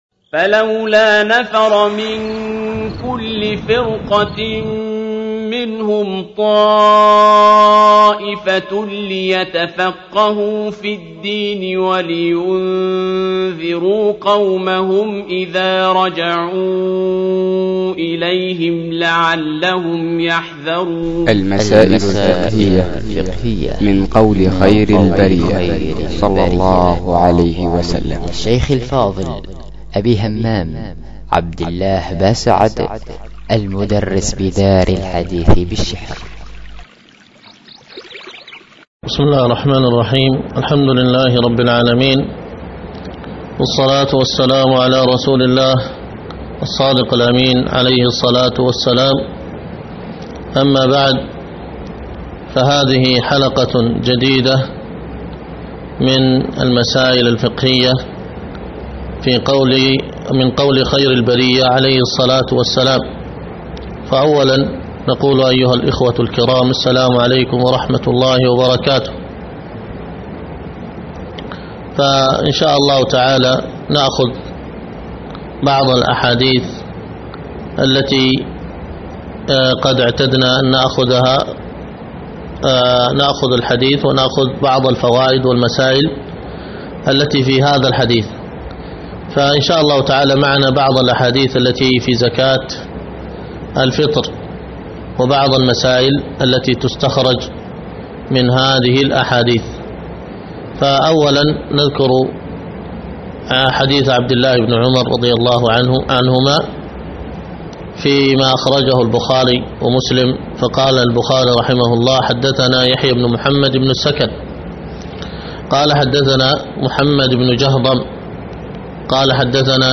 المسائل الفقهية من قول خير البرية 14 | المسائل الفقهية من قول خير البرية - برنامج إذاعي ( مكتمل )